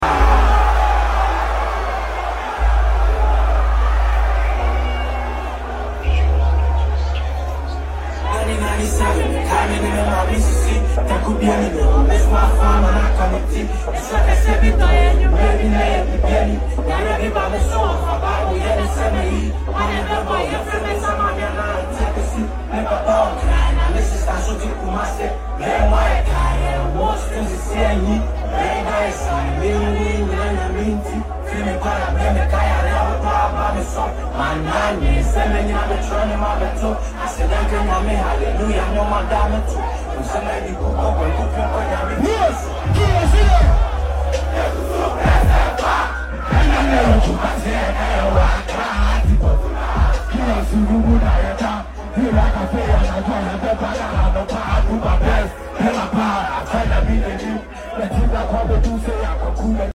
live on stage